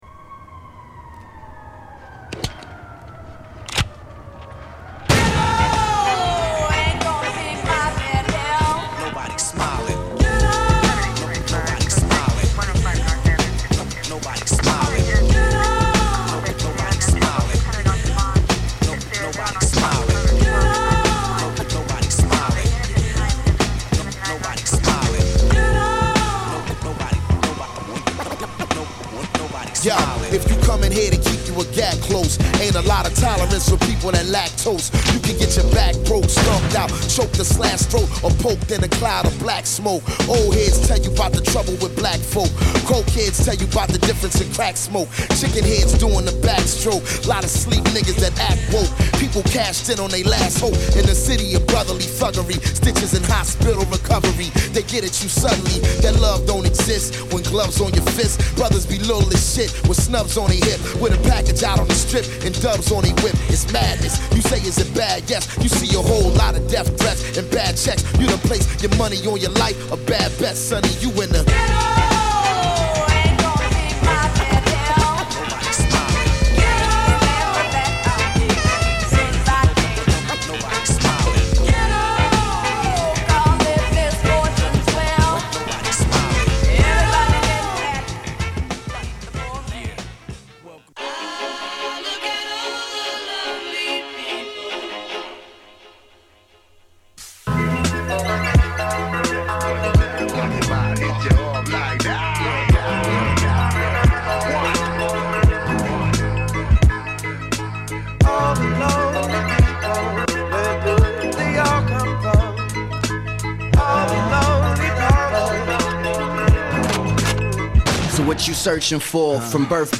- Dirty *